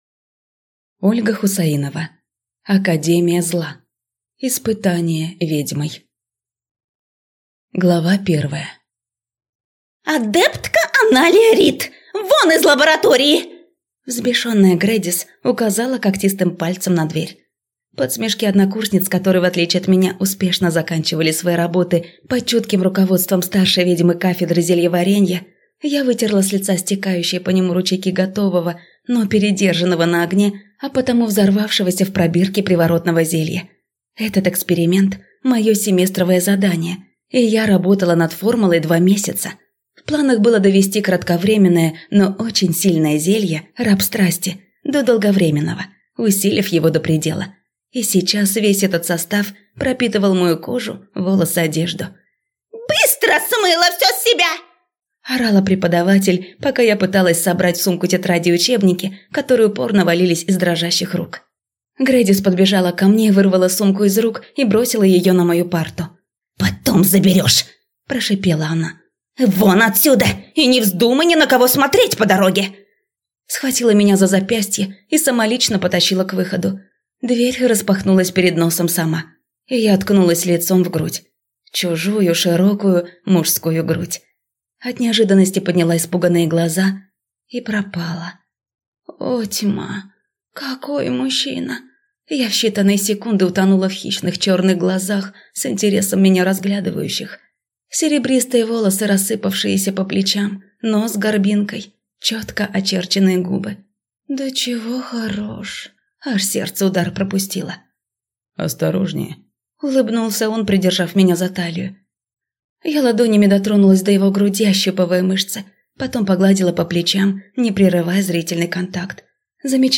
Аудиокнига Академия Зла. Испытание ведьмой | Библиотека аудиокниг